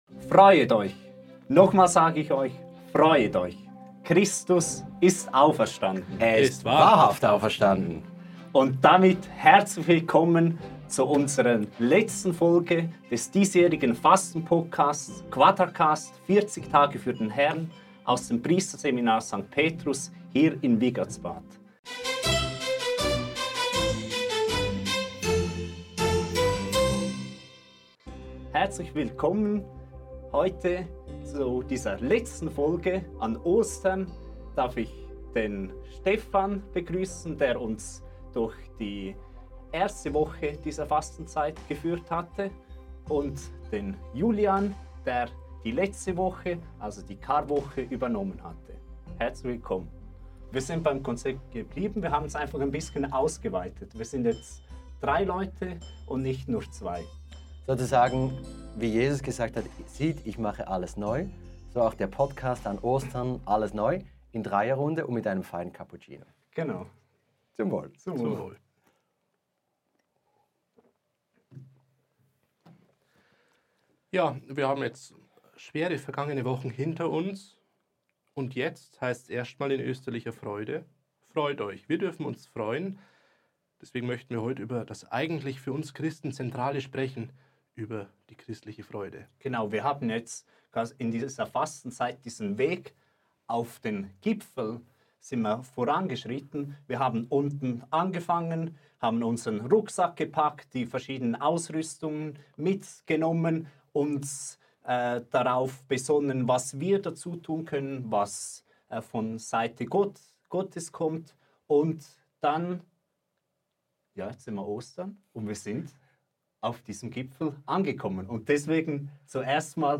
So sind wir heute zu dritt! Wir reden über die Kunst der wahren Freude, die Schlummertaste am Wecker und andere wichtige Dinge für ein erfülltes Leben.